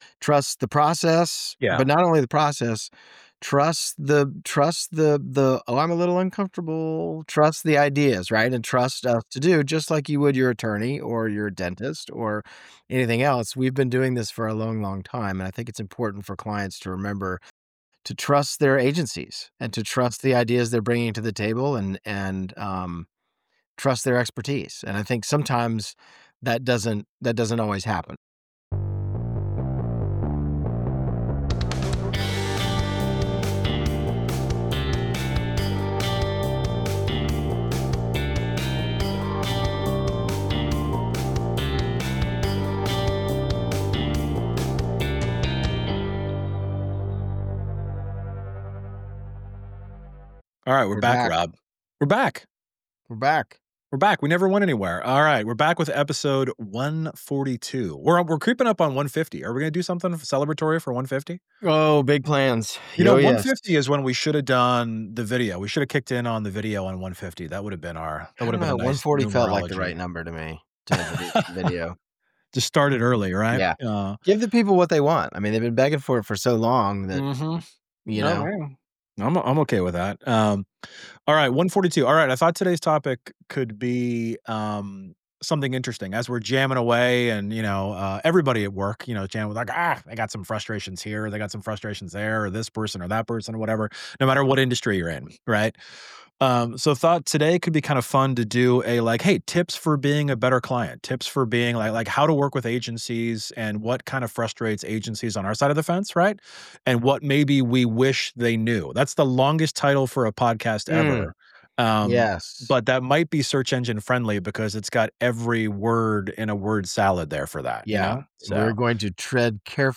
They discuss the importance of trusting agency expertise, contextual thinking in design decisions, and the challenges of creating custom solutions for each client. Through candid conversation, they share tips on fostering creativity, collaboration, and effective communication to navigate the fast-paced, digital-driven world of advertising today.